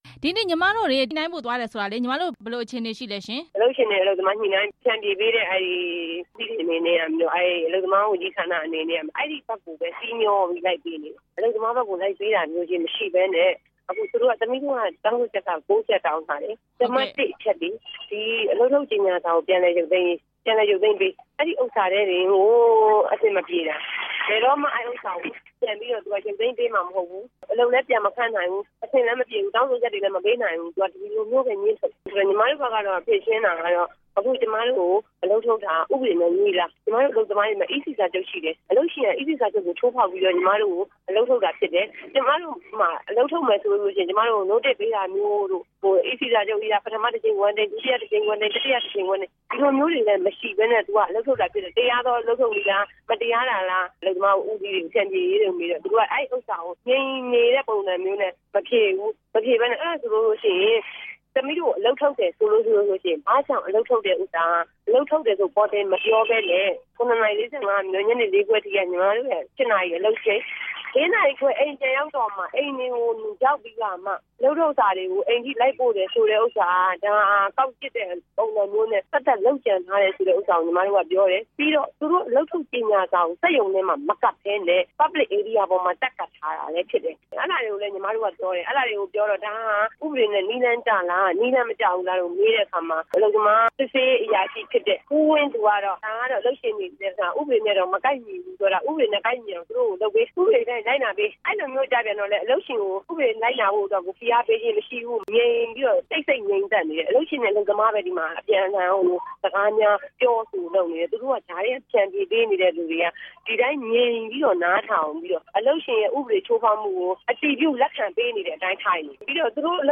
ဖုယွင်အထည်ချုပ်စက်ရုံ အလုပ်သမားသမဂ္ဂနဲ့ ဆက်သွယ်မေးမြန်းချက်